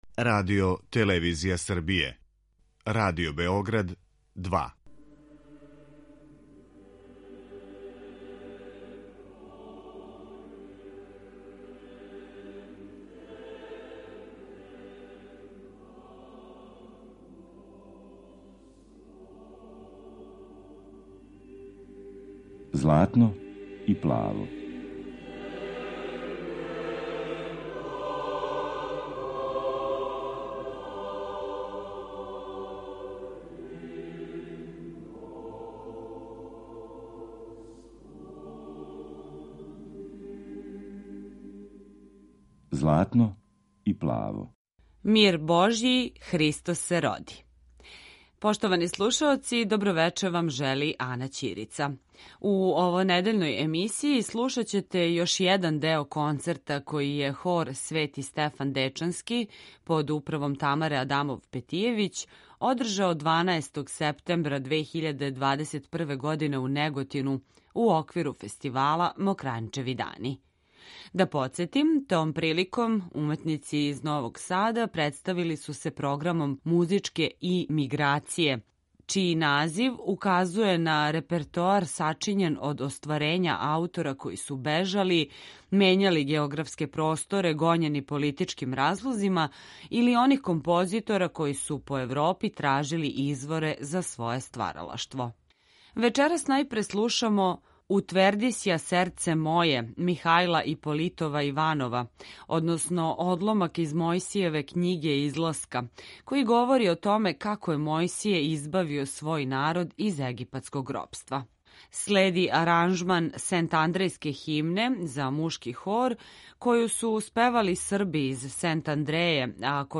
Емисија посвећена православној духовној музици.